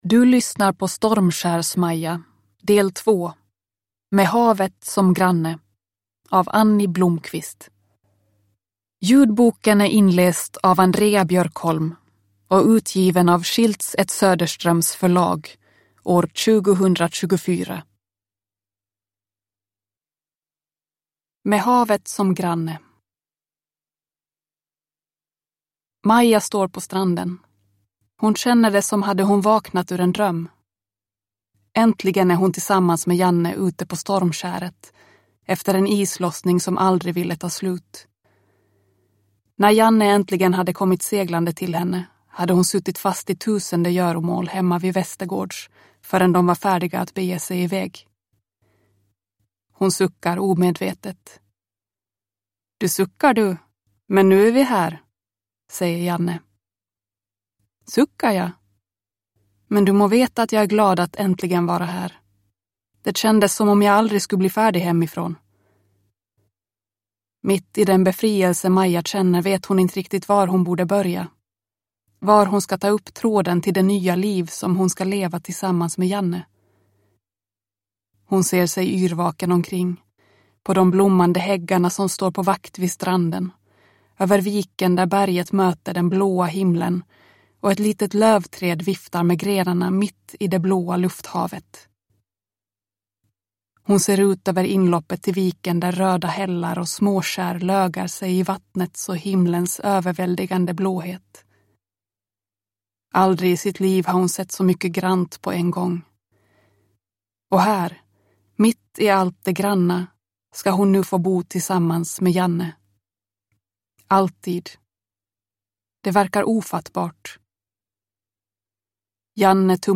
Stormskärs Maja del 2. Med havet som granne – Ljudbok